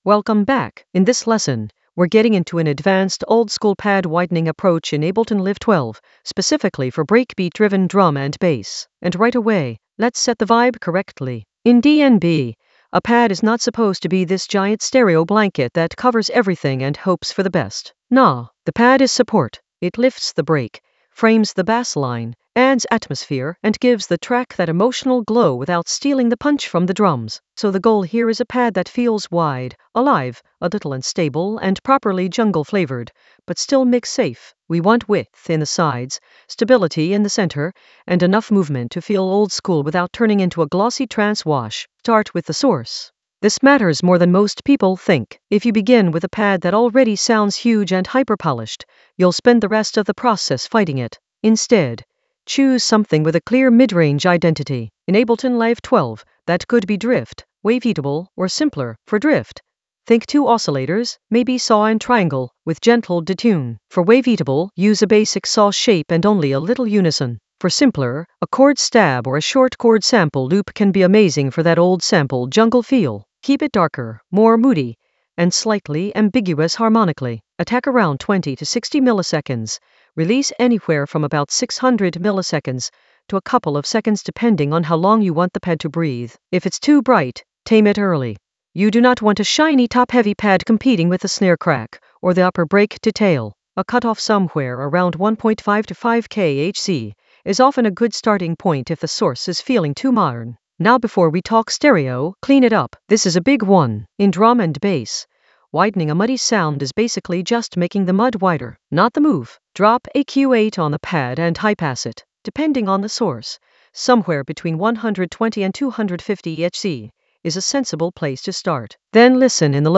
Narrated lesson audio
The voice track includes the tutorial plus extra teacher commentary.
An AI-generated advanced Ableton lesson focused on Oldskool tutorial: pad widen in Ableton Live 12 in the Breakbeats area of drum and bass production.
oldskool-tutorial-pad-widen-in-ableton-live-12-advanced-breakbeats.mp3